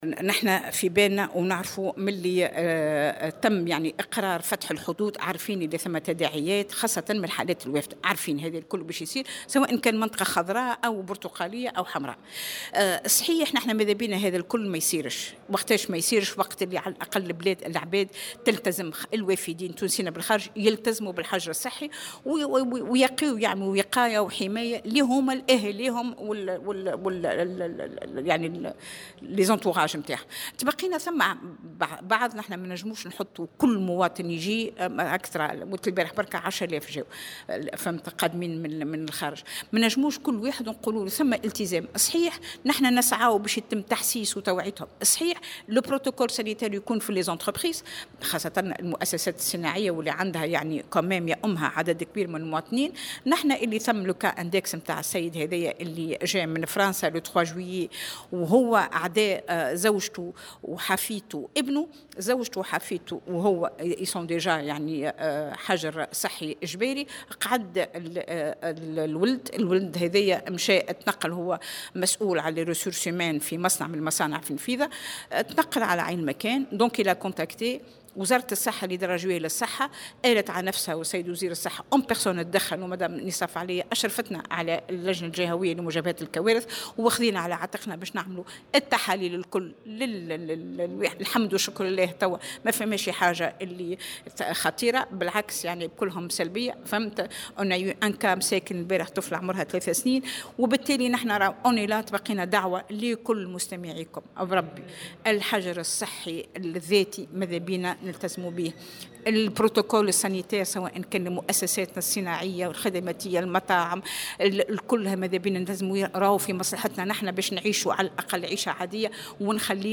وأضافت في تصريح لـ"الجوهرة أف أم" أن تسجيل حالات إصابة جديدة، بفيروس كورونا كان متوقعا، بعد قرار فتح الحدود، وفي ظل تواصل الرحلات بشكل يومي.